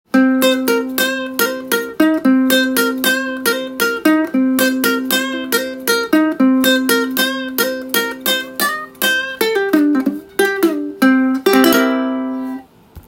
コンサートウクレレが入っていました♪
試しにこのマーチンウクレレを弾かせて頂きました
凄い。。ビックリするぐらい鳴ります！
驚きの低音と中音がします。